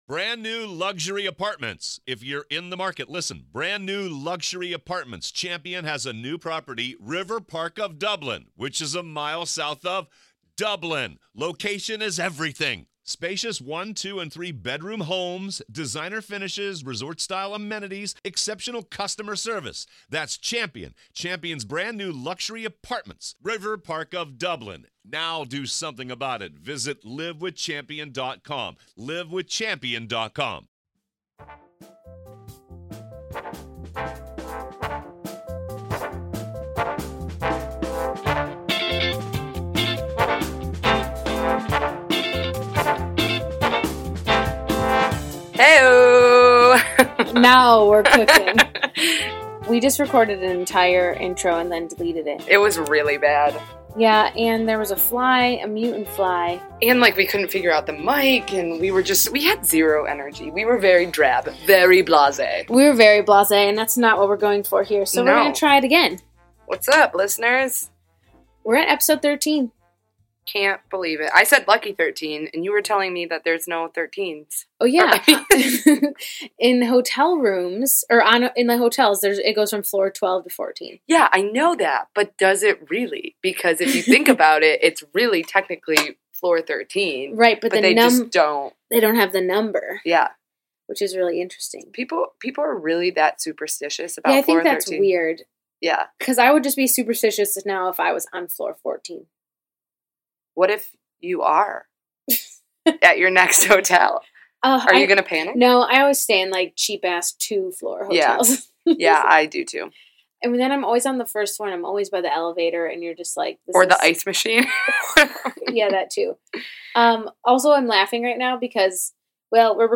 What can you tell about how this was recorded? recording in the basement this episode